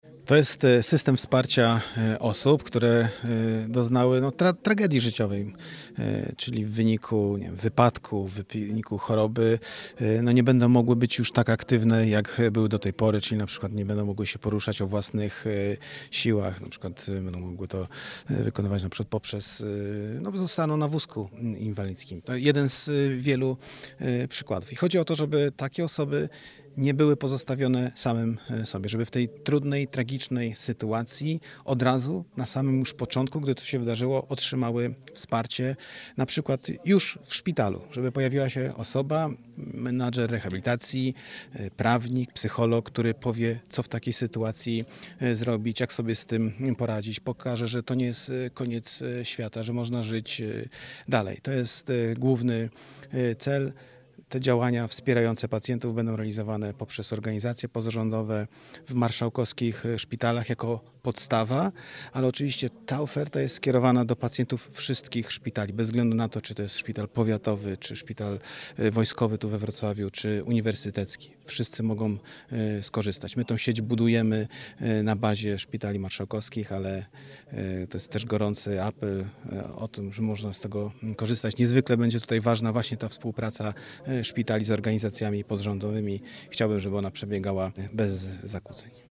– Stworzyliśmy Dolnośląską Sieć Wsparcia, czyli miejsca funkcjonujących przy szpitalach, które pomogą osobom, które nagle doświadczyły niepełnosprawności poradzić sobie w nowej rzeczywistości – mówi Wicemarszałek Województwa Dolnośląskiego Marcin Krzyżanowski.